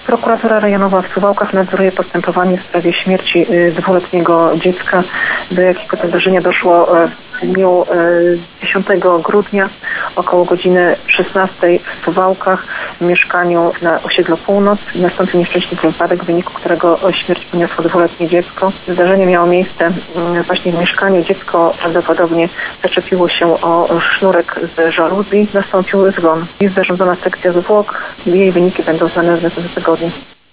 Jak wynika ze wstępnych ustaleń śledczych, prawdopodobną przyczyną śmierci dziecka było uduszenie żaluzją podczas zabawy. – Zdarzenia to rozpatrujemy w kategoriach nieszczęśliwego wypadku – mówi Joanna Orchowska, Prokurator Rejonowy w Suwałkach.